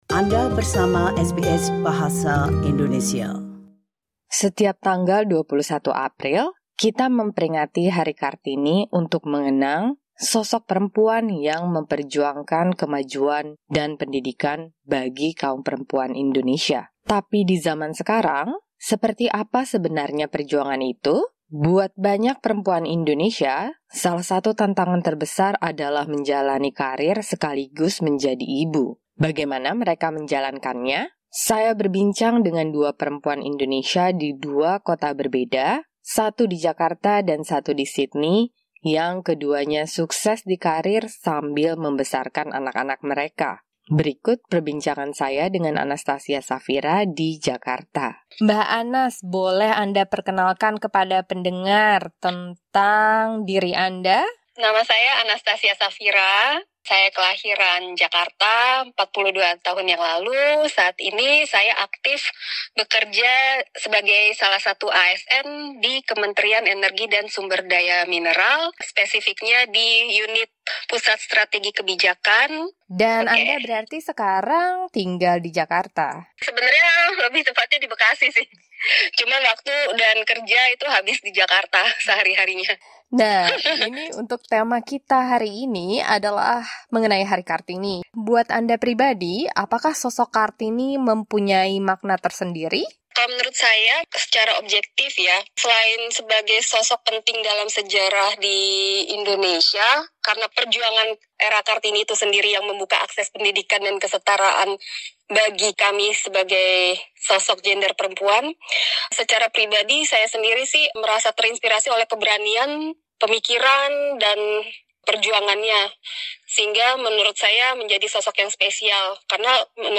Two Indonesian women in Jakarta and Sydney share their stories of juggling motherhood and career, and what it means to be a Kartini in today's world.